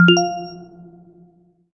gui_confirm.wav